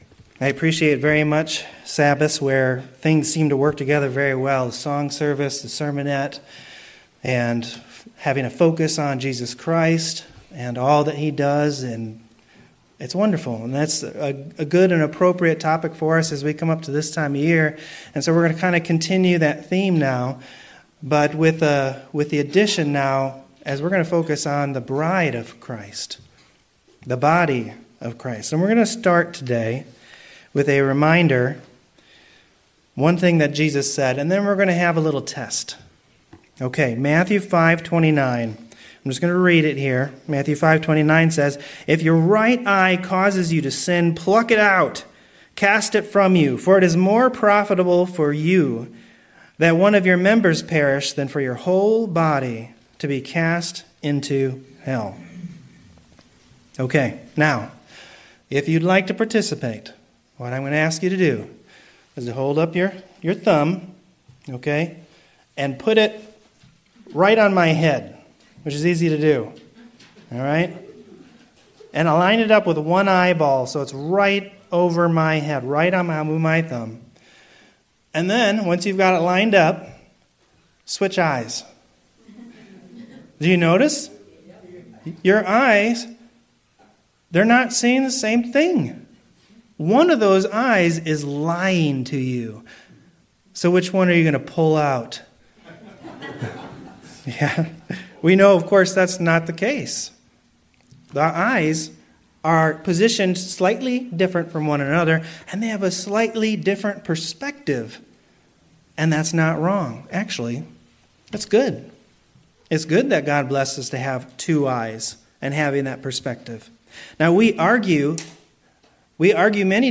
Sermons
Given in Cleveland, OH